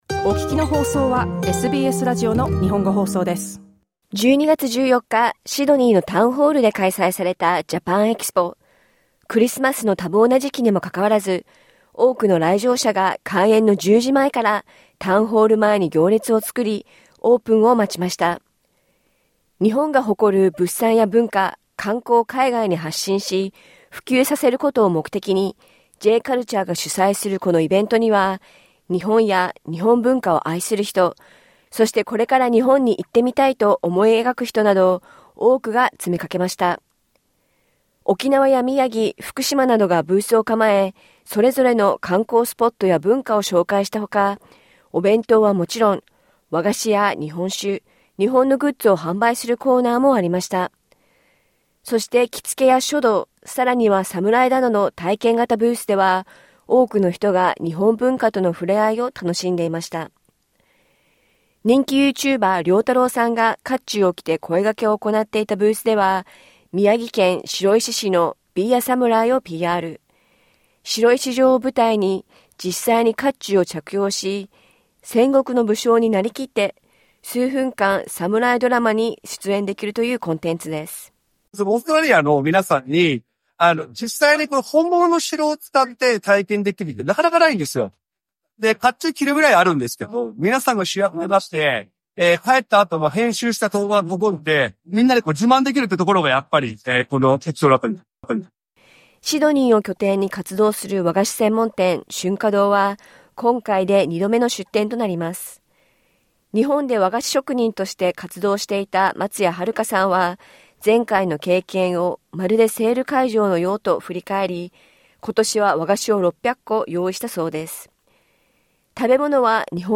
JAPAN EXPOイベントリポート